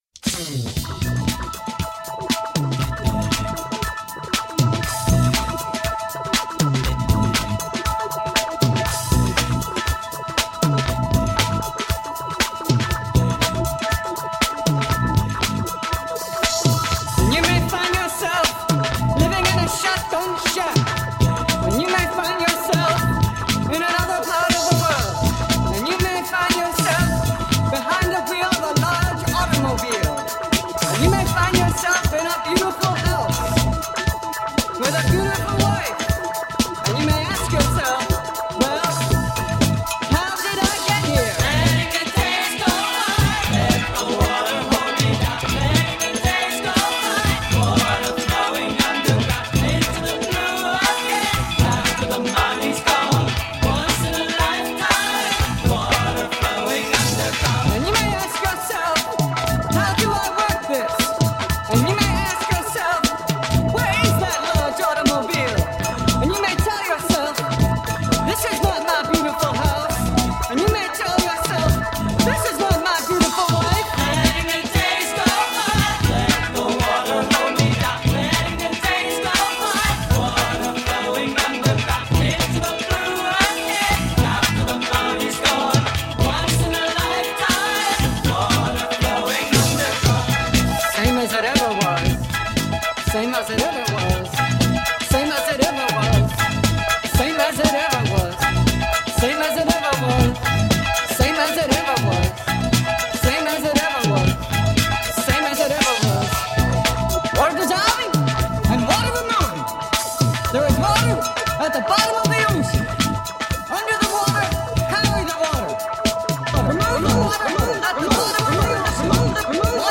talking.mp3